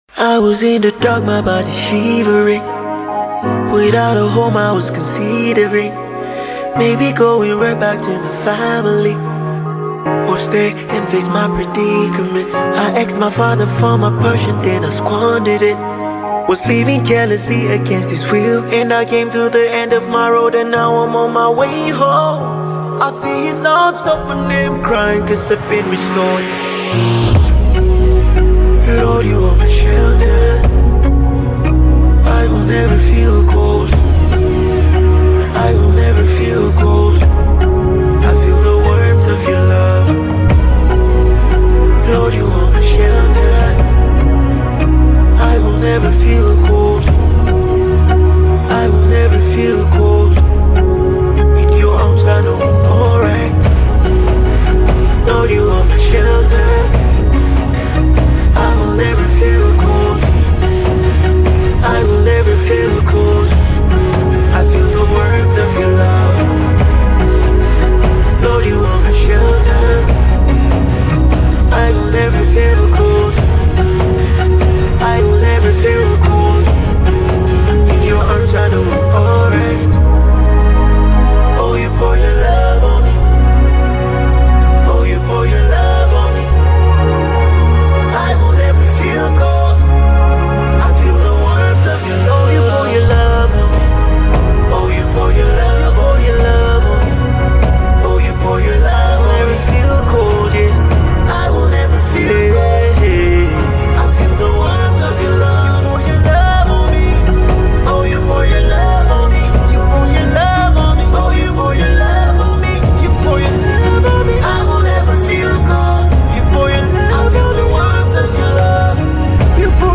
Gospel rapper
new single